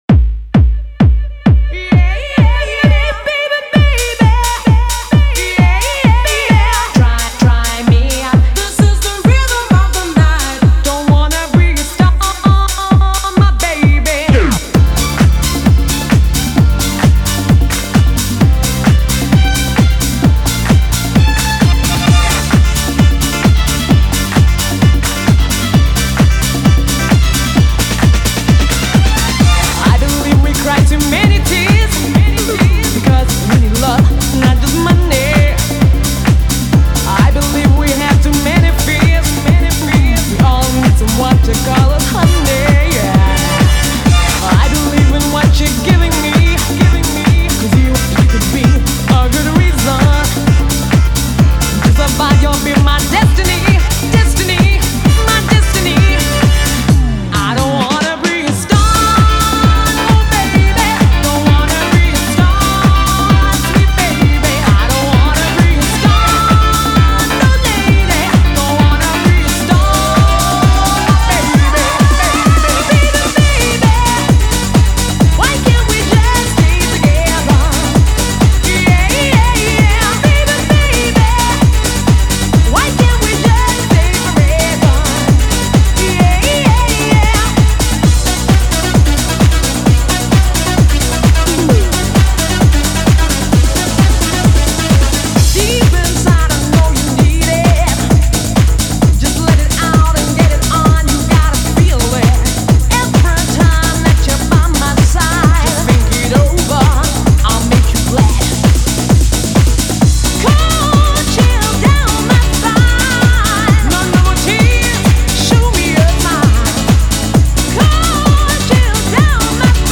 Bring the Ultimate 90s Dance Energy to Miami Nights
modern club rhythms